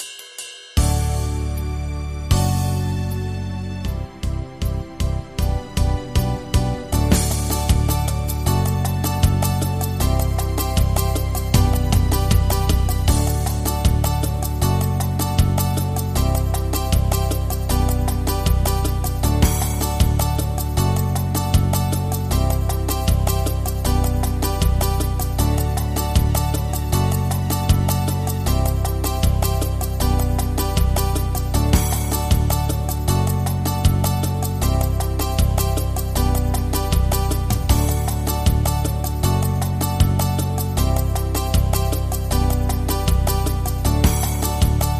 midi/karaoke